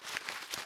x_enchanting_scroll.9.ogg